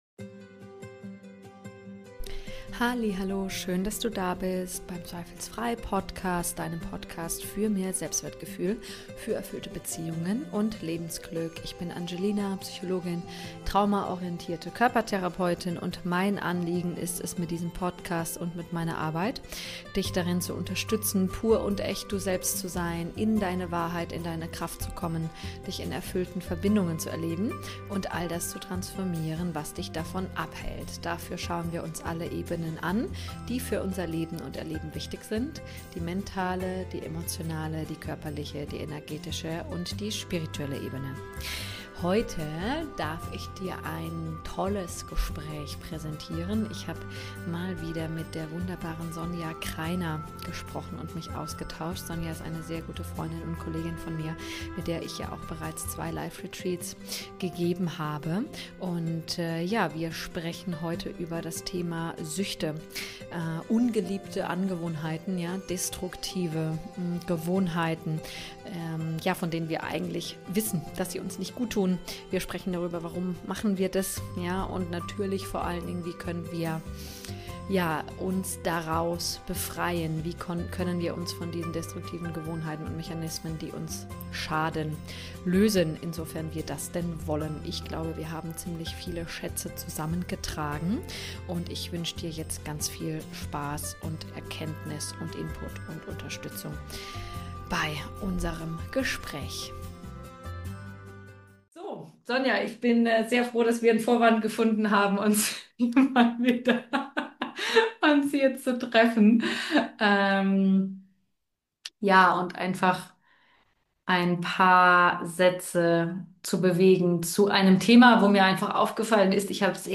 Definitiv mal wieder Zeit für ein Interview